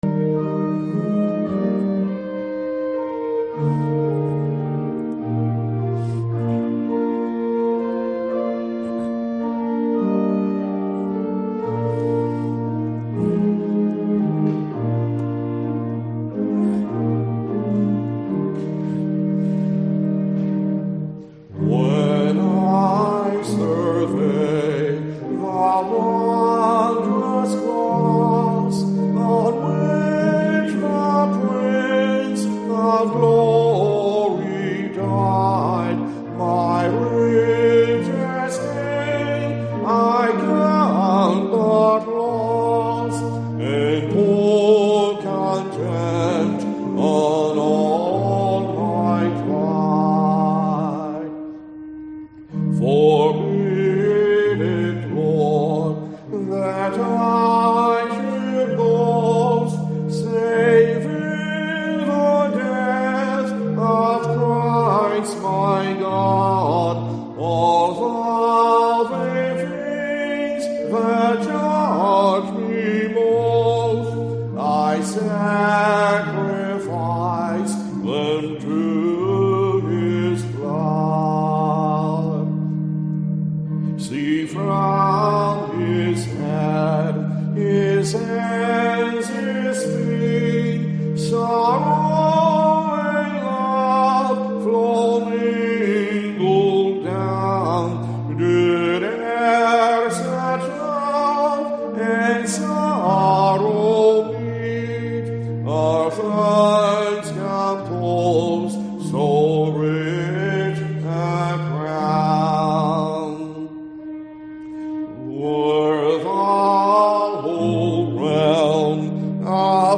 St-Matthew-Good-Friday-Worship-2.mp3